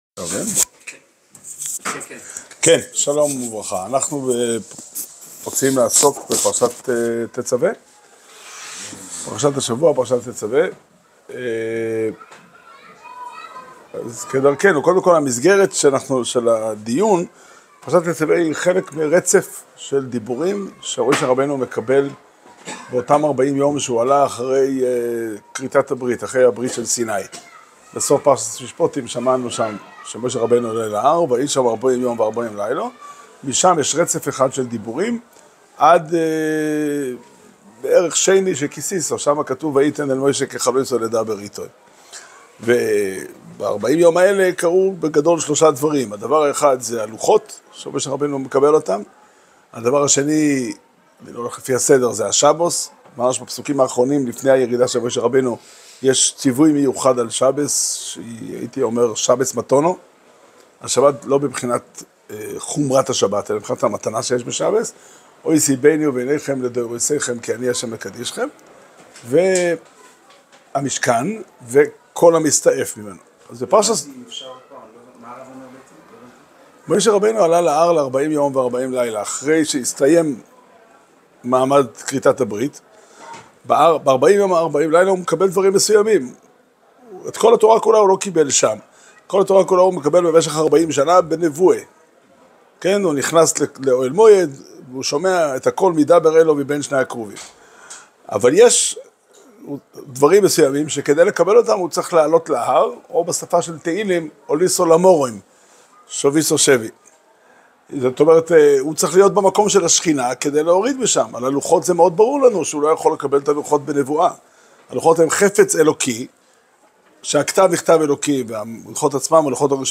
שיעור שנמסר בבית המדרש פתחי עולם בתאריך ג' אדר תשפ"ה